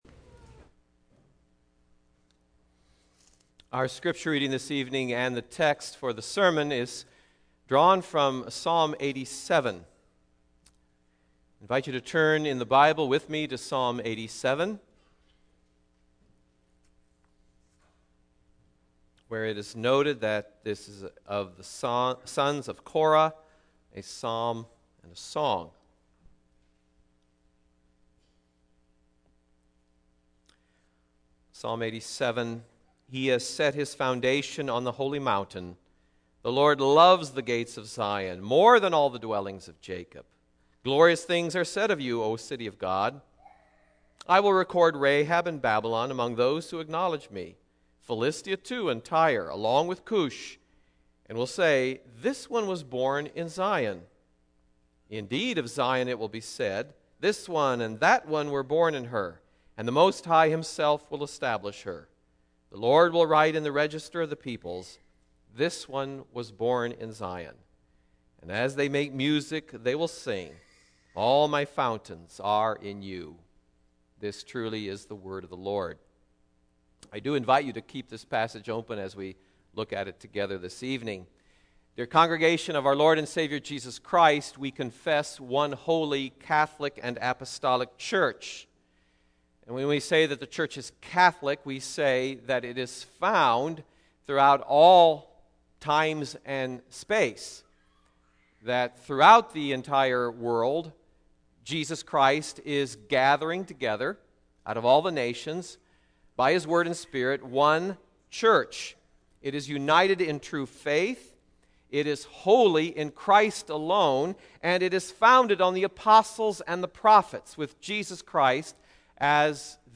Single Sermons Passage